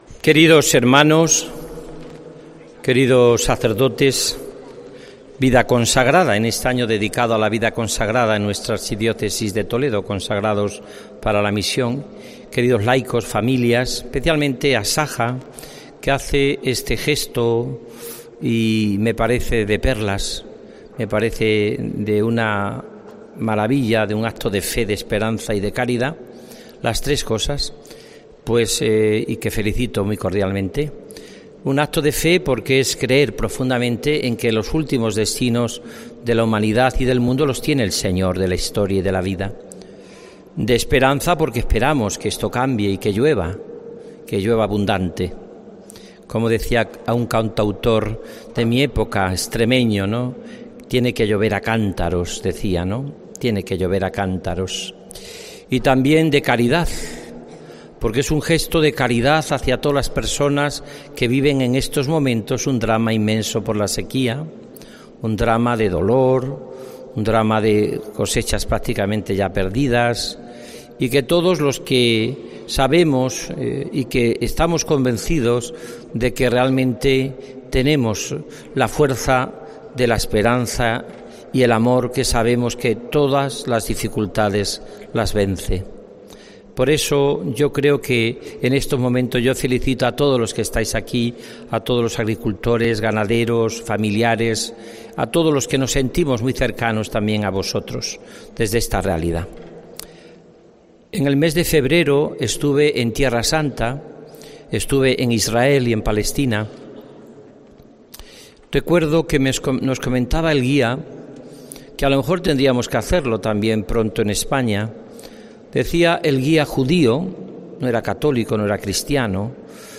Homilía en la misa rogativa donde se ha pedido por la lluvia